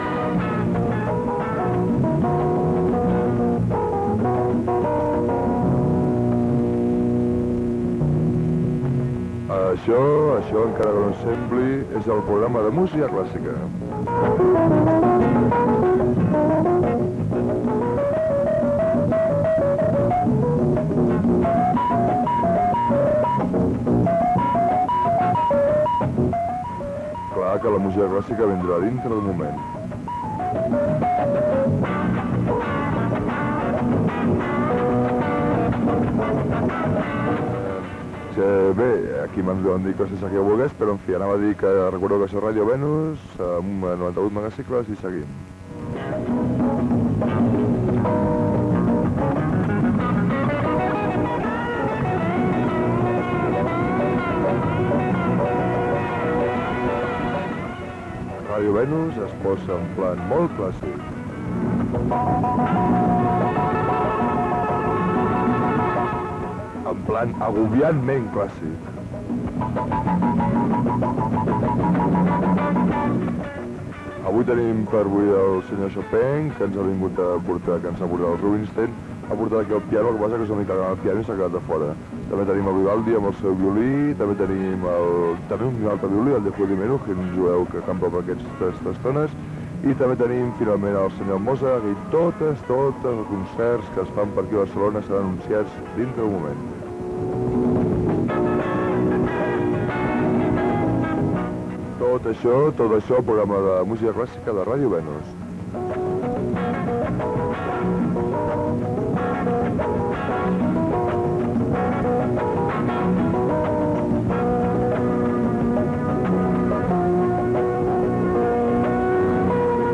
Programa de música clàssica
Musical